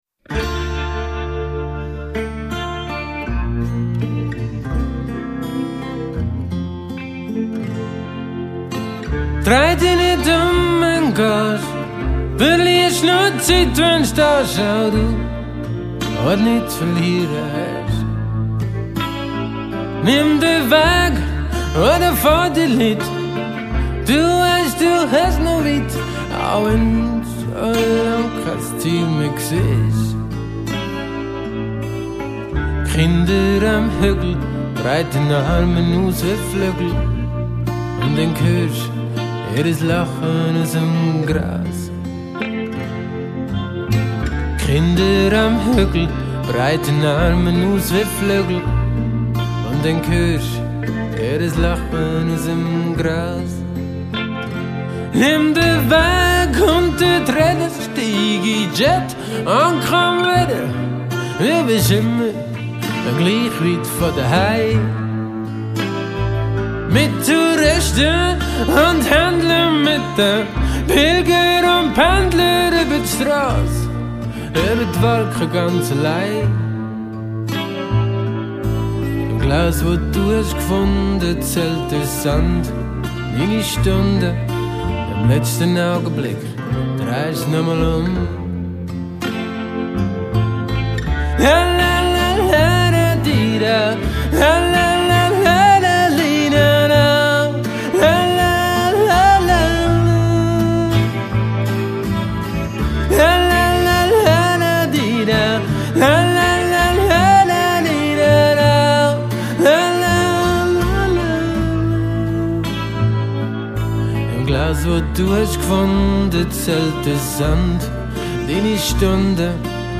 Bass
Gesang, Gitarren